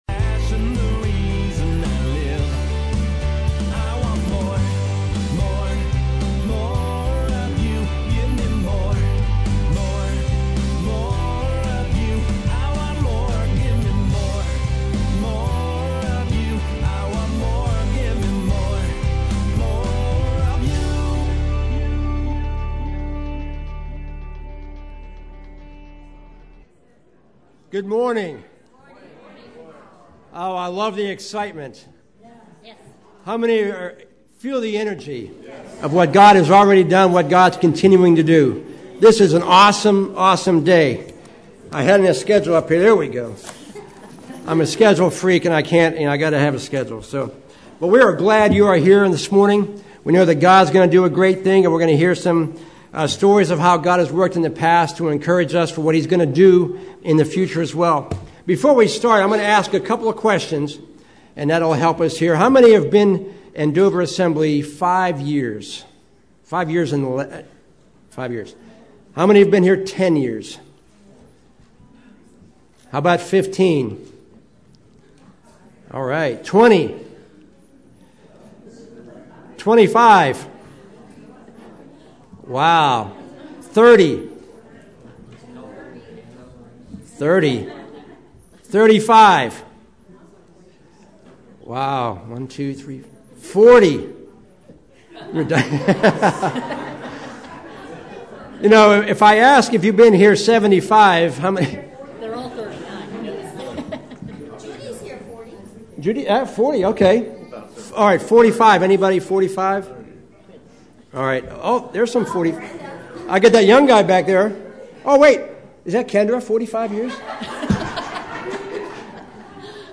Today's service honored the past while preparing for the future.